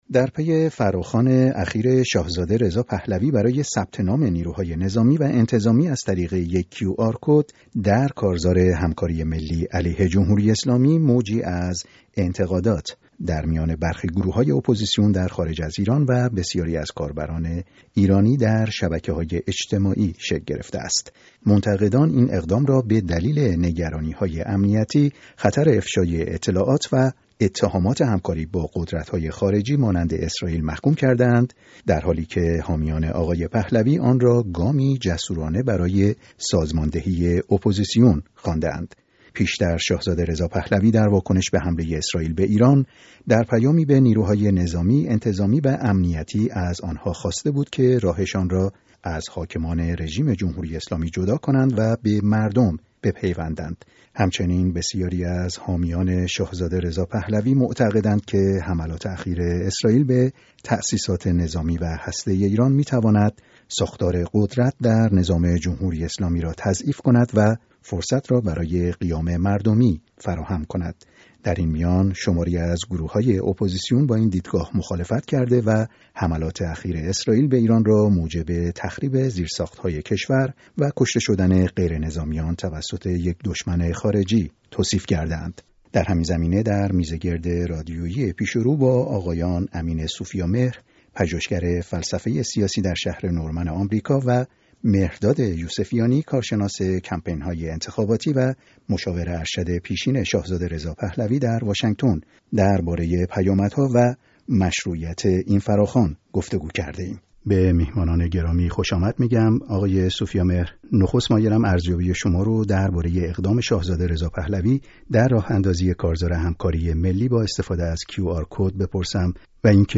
گفت‌وگوی رادیویی